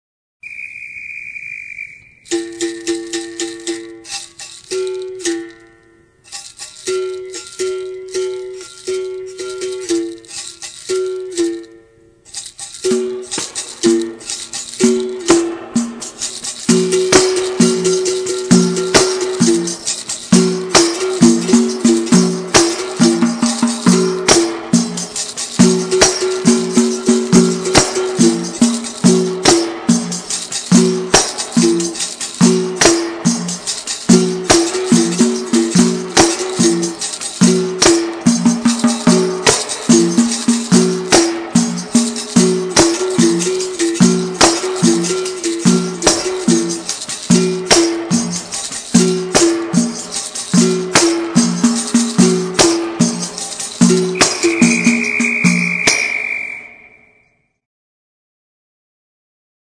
Toque são Bento Pequeno
sao_bento_pequeno.mp3